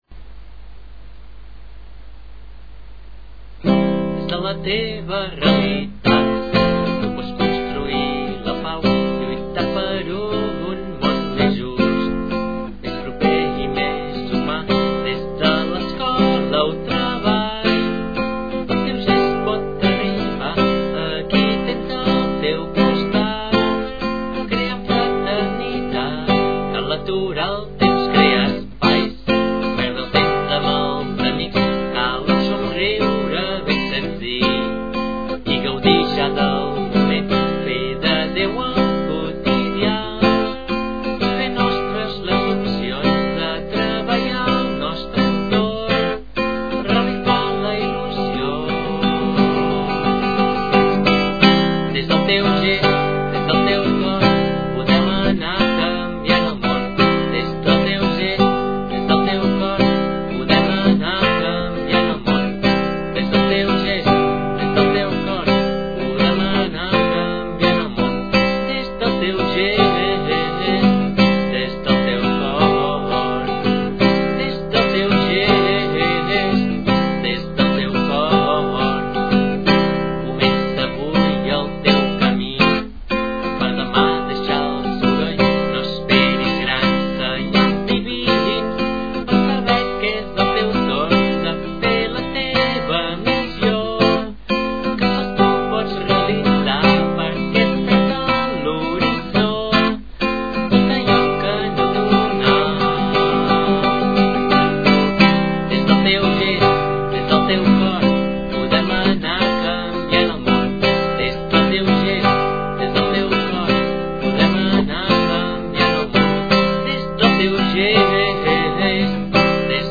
a la flauta travessera
guitarra i veu.
i formar el grup de guitarra i flauta del Convent d’Arenys.